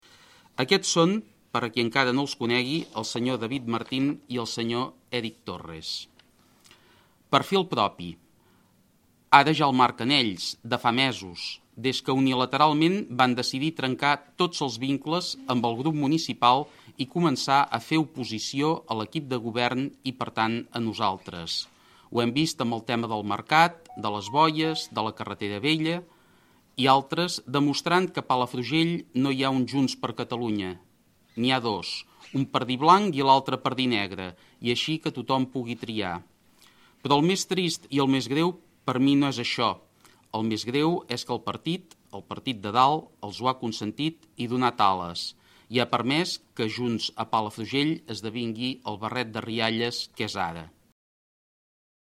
Ho ha fet en una roda de premsa en què ha assegurat que diu “prou abans d’hora” a causa de la “fractura oberta que s’ha anat generant a nivell local de Palafrugell entre el comitè local i el grup municipal de Junts”.